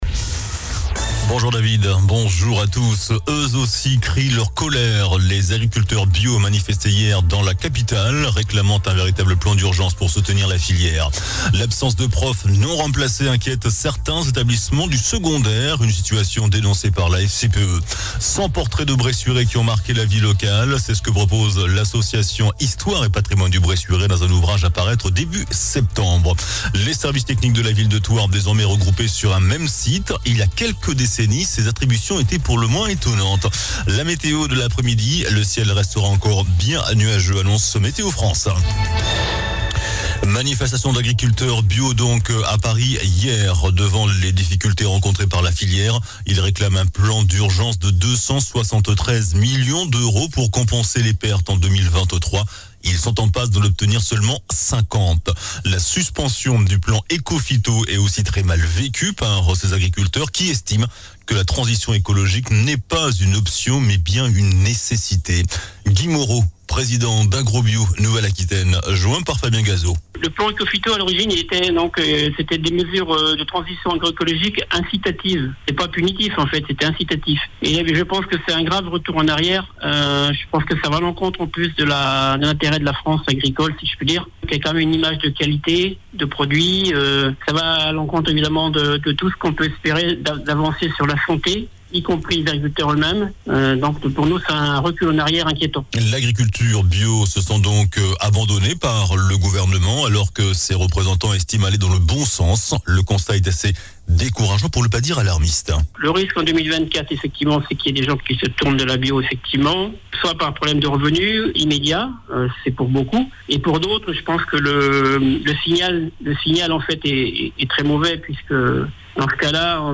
JOURNAL DU JEUDI 08 FEVRIER ( MIDI )